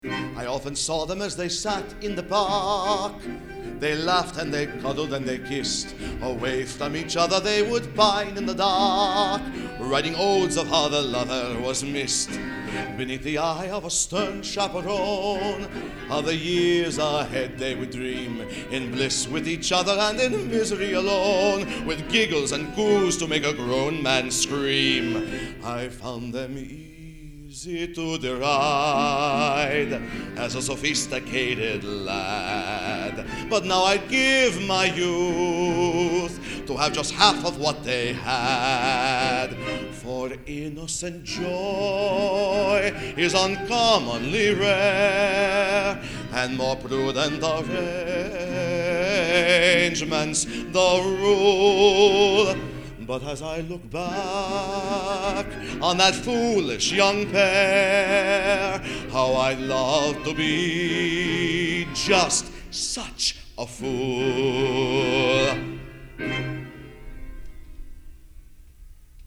A new play with music